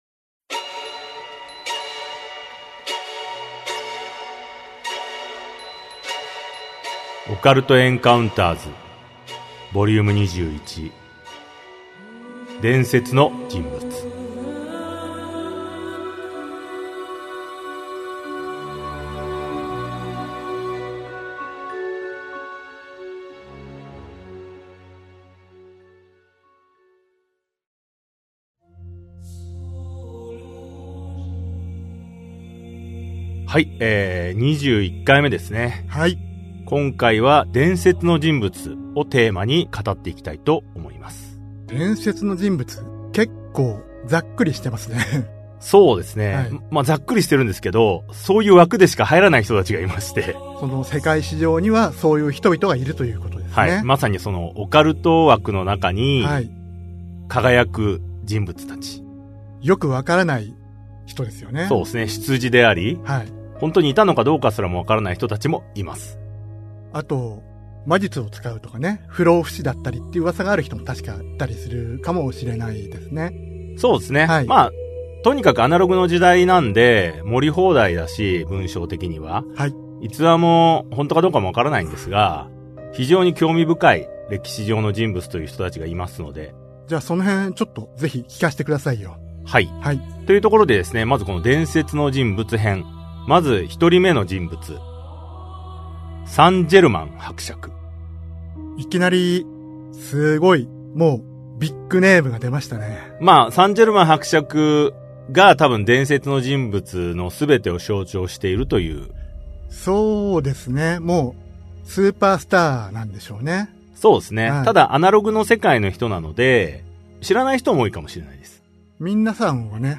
[オーディオブック] オカルト・エンカウンターズ オカルトを推理する Vol.21 伝説の人物編1